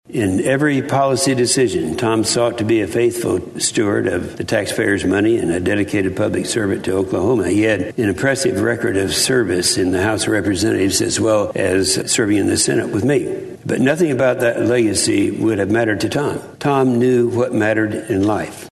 Senator Jim Inhofe also offered his remarks on the Senate floor in tribute to the late Dr. Coburn. The Senator said there was no one like Dr. Tom Coburn.